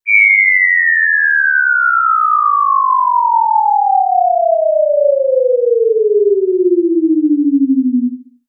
CartoonGamesSoundEffects
Falling_v2_wav.wav